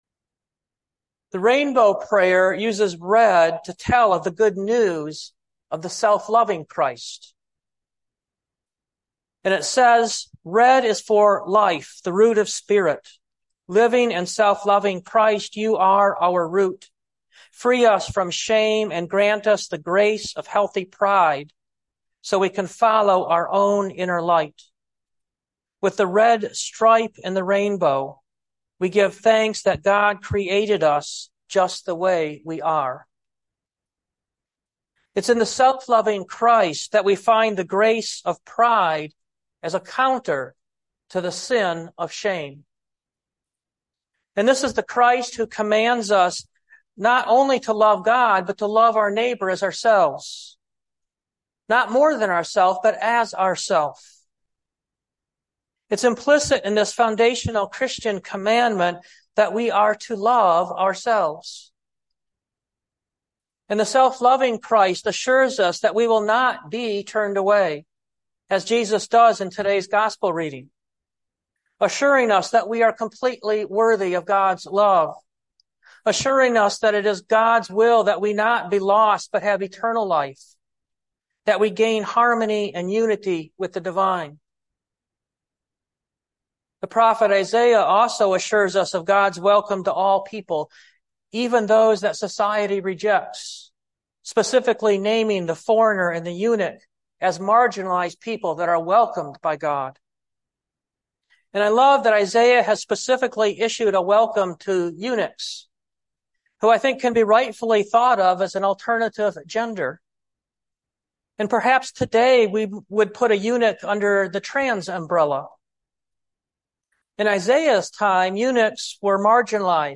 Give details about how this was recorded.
The first part of the message was cut off the recording.